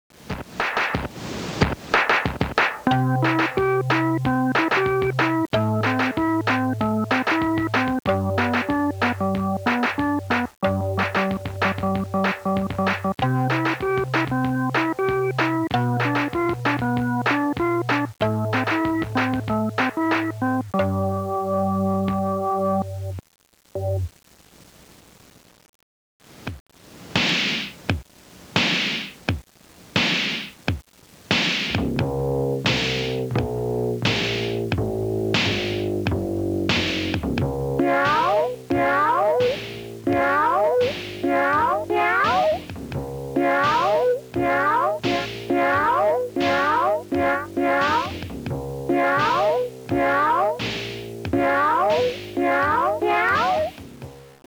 Funny sound effects